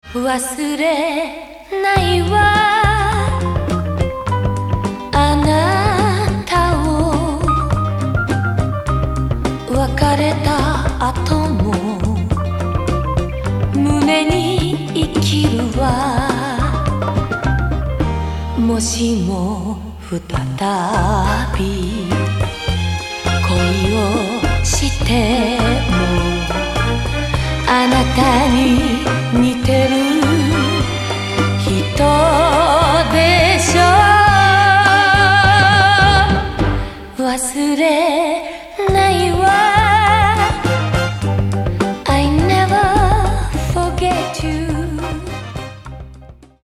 ジャンル：和製ポップス